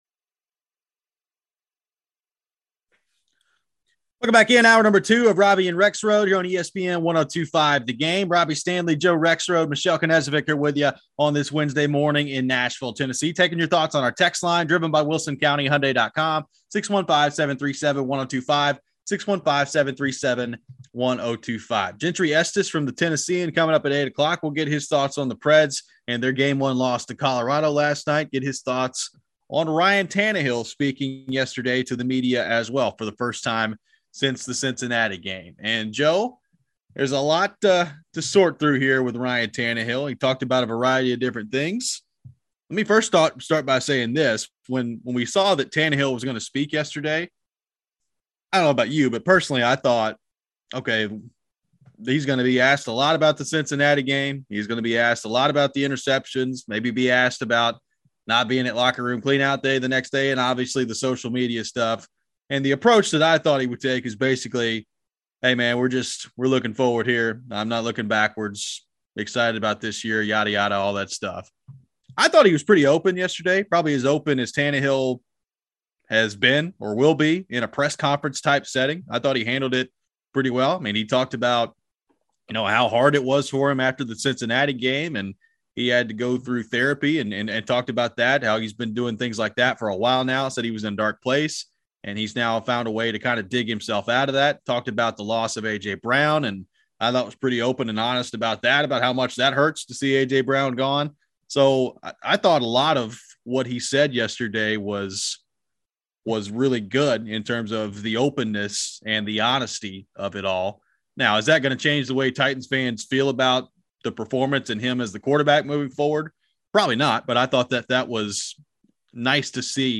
Is this being blown out of proportion? We take your thoughts on the text and phone line.